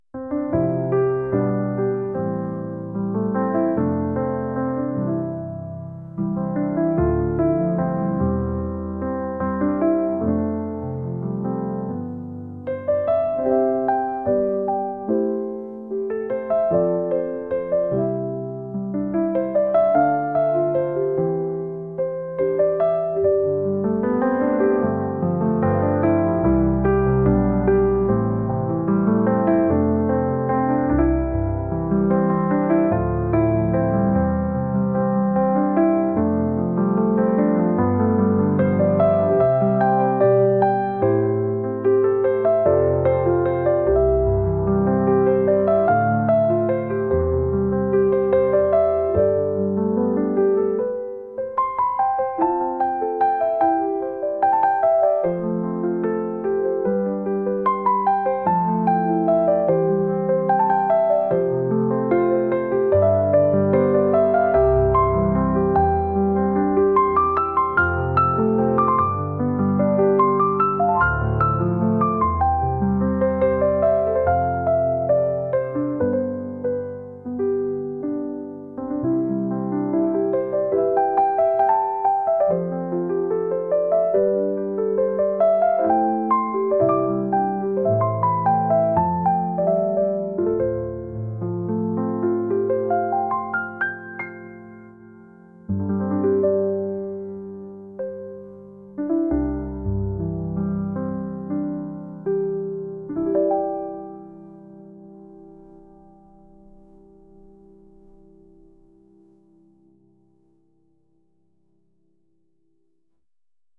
スローテンポからのこれから何か起こるかもしれないと予感させるようなピアノ曲です。